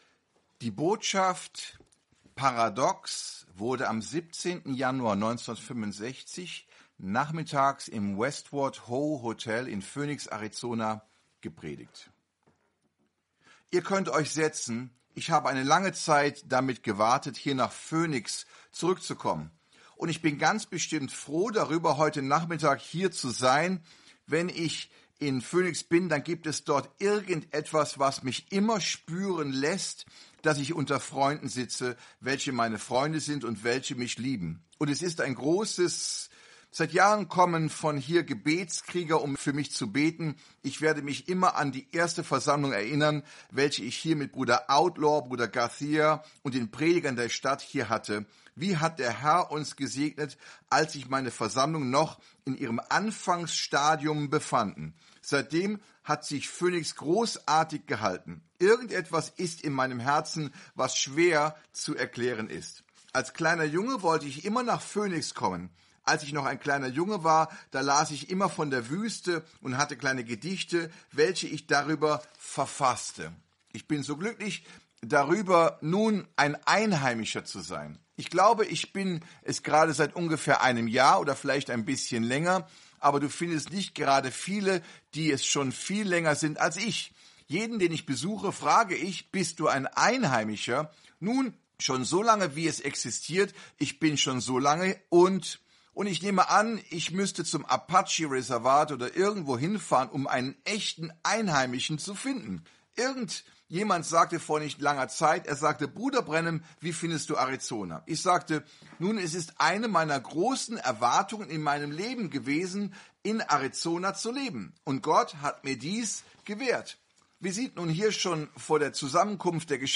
Dieses Portal gibt Ihnen die Möglichkeit, die ca. 1200 aufgezeichneten Predigten von William Marrion Branham aufzurufen, zu lesen oder zu hören.